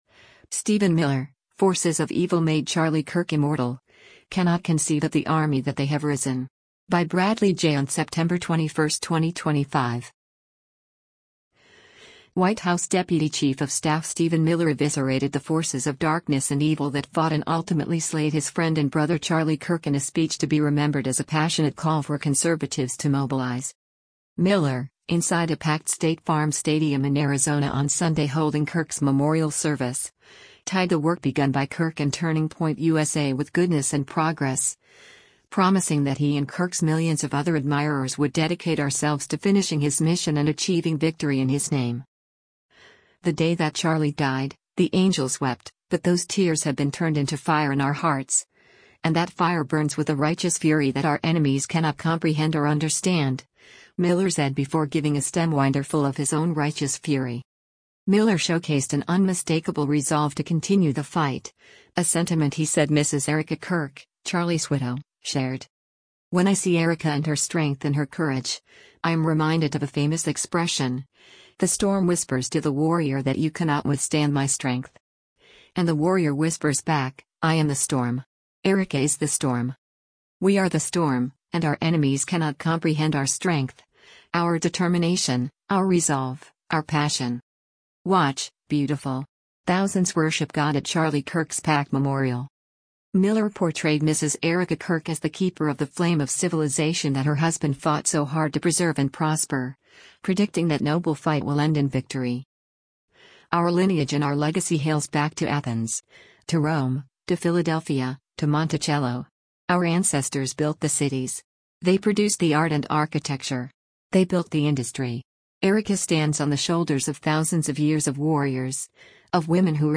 White House Deputy Chief of Staff Stephen Miller speaks during the public memorial service